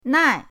nai4.mp3